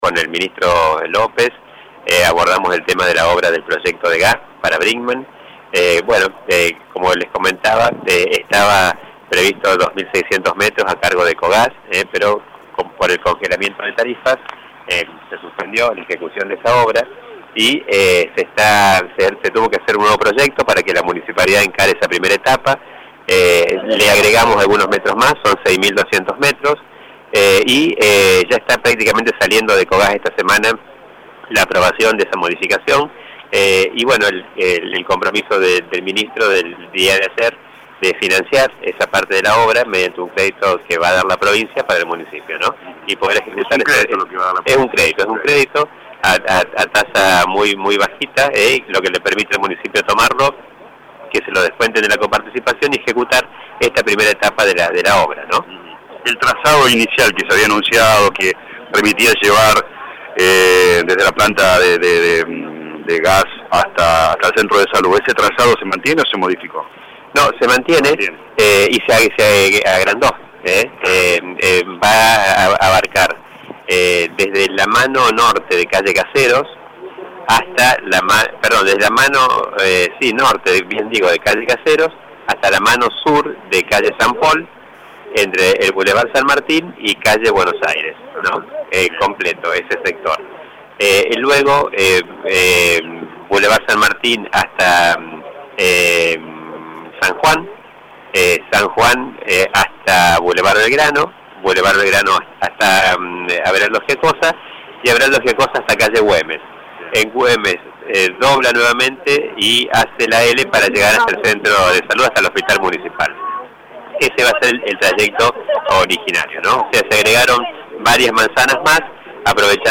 El intendente de la ciudad dialogó con LA RADIO 102.9 y confirmó que comenzó la ejecución del Programa Reconstruir de las 33 viviendas.